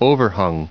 Prononciation du mot overhung en anglais (fichier audio)
Prononciation du mot : overhung